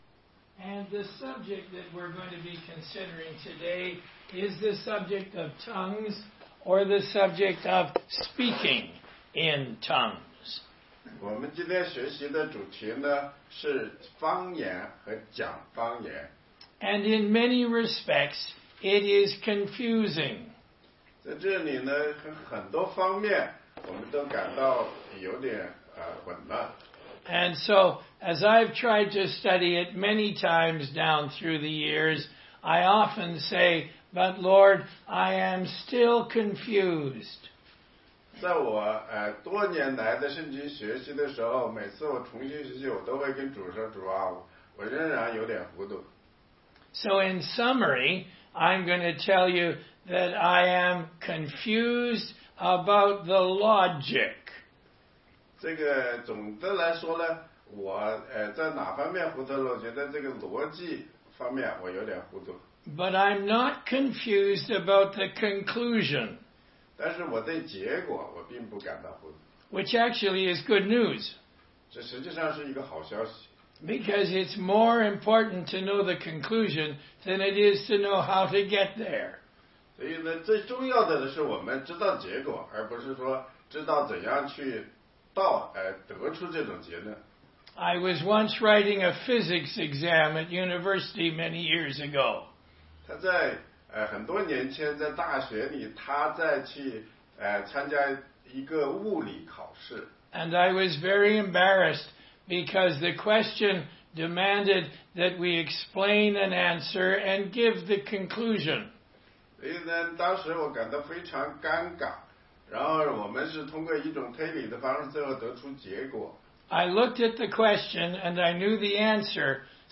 16街讲道录音 - 哥林多前书14章22-40节：关于方言和女人说话的教导